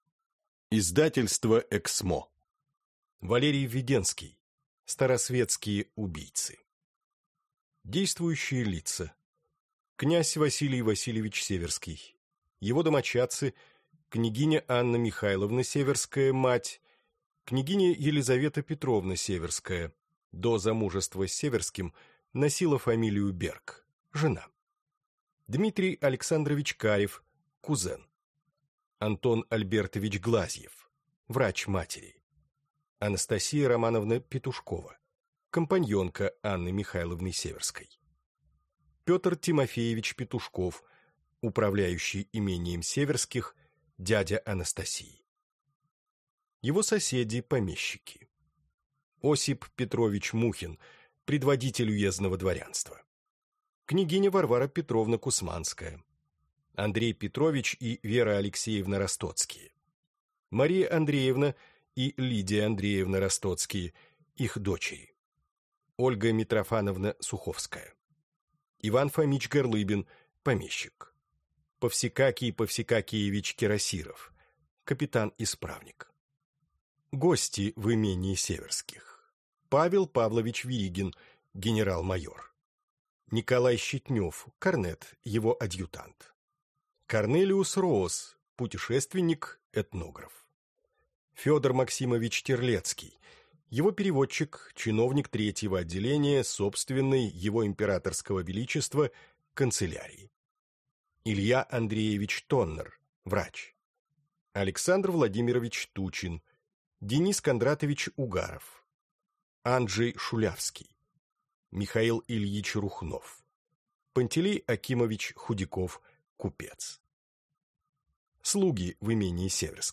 Аудиокнига Старосветские убийцы | Библиотека аудиокниг